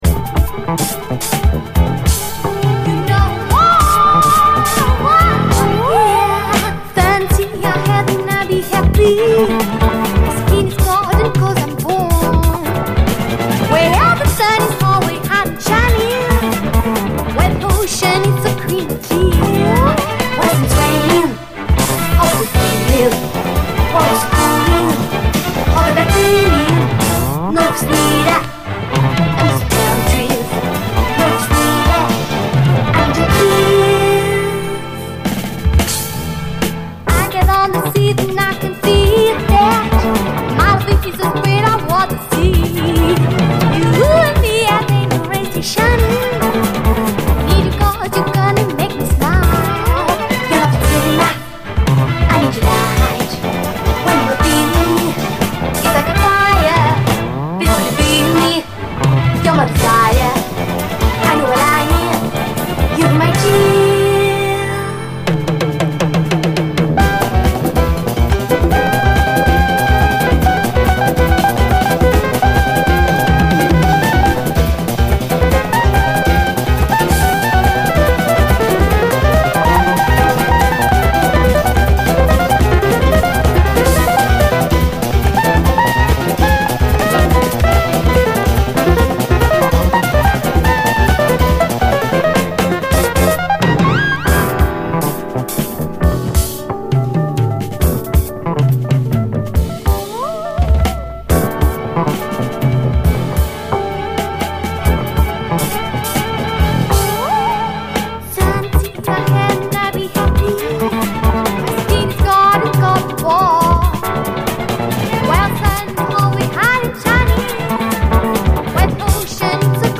SOUL, JAZZ FUNK / SOUL JAZZ, 70's～ SOUL, JAZZ
疾走系ブラジリアン・フュージョン・ソウル
可憐な女性ヴォーカルが舞う、躍動感がヤバいレアグルーヴ的な一曲！